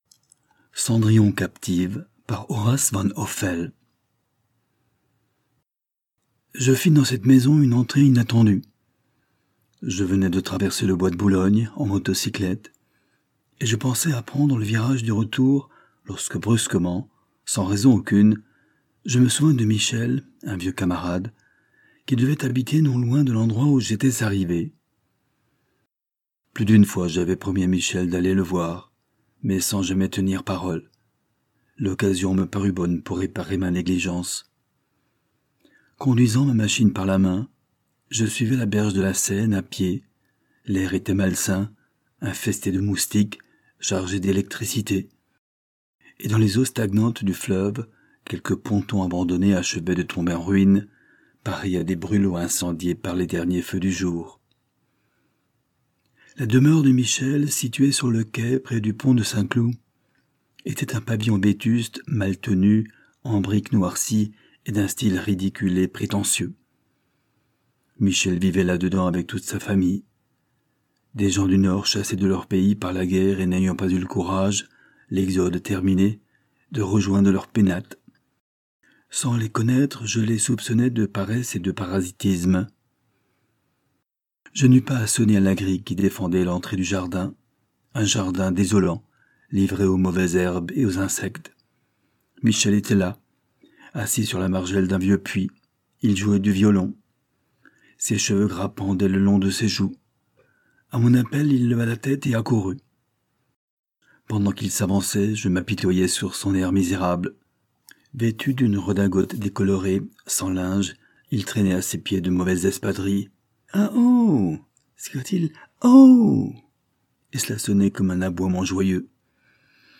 Livre audio gratuit : Cendrillon captive